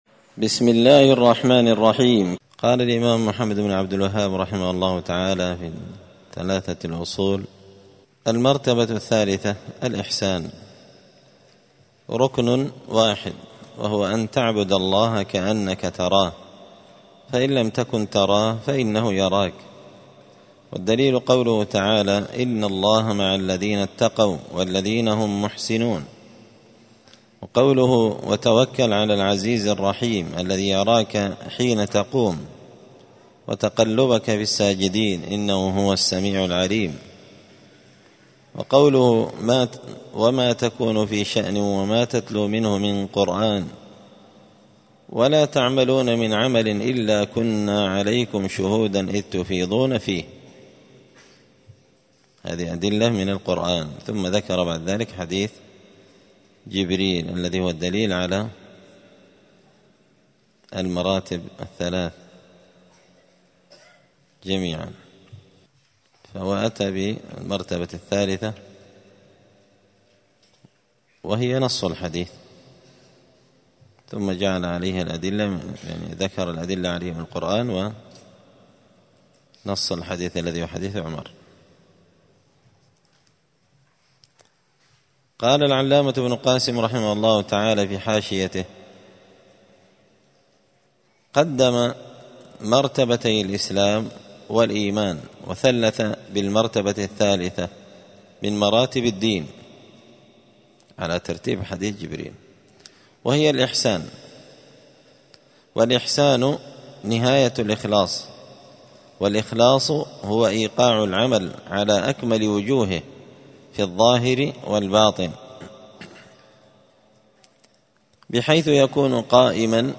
دار الحديث السلفية بمسجد الفرقان بقشن المهرة اليمن
*الدرس الثامن والعشرون (28) من قوله {المرتبة الثالثة الإحسان وهو ركن واحد…}*